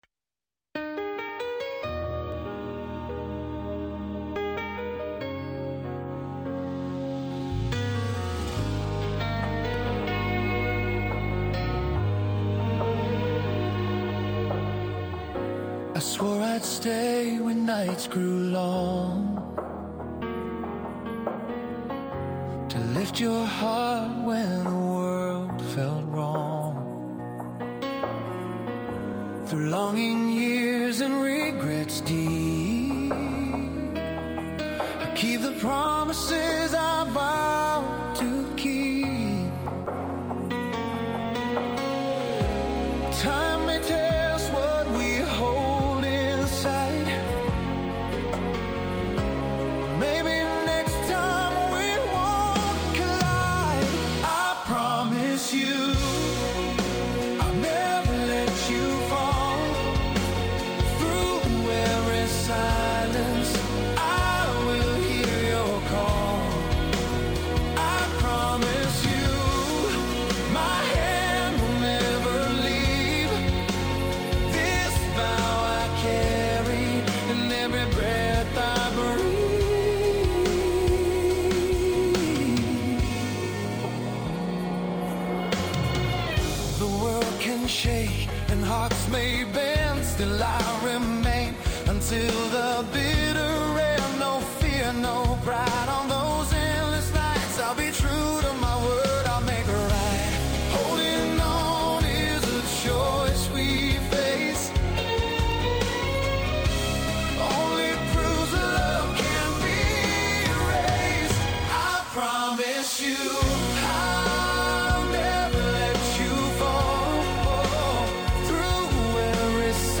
Adult Contemporary